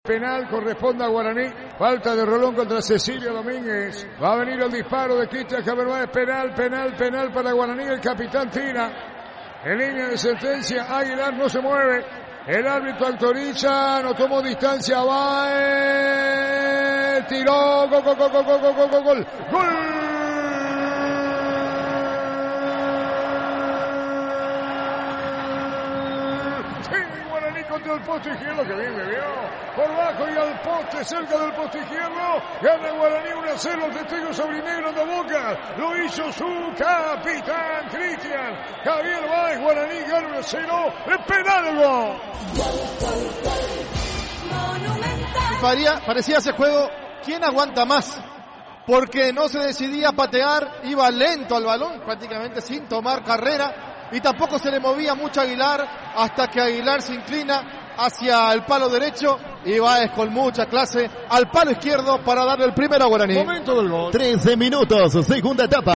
Relatos y comentarios del equipo de Fútbol a lo Grande.